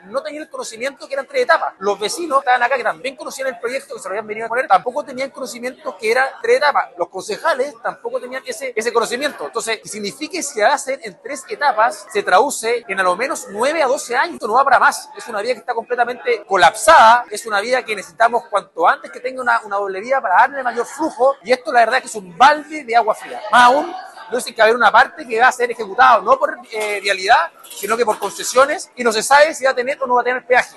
Los trabajos podrían terminar en hasta 12 años. Ante ello, el alcalde de Puerto Montt, Rodrigo Wainraihgt, señaló que se trata de una demora excesiva.
rodrigo-wainraihgt-alcalde-cuna.mp3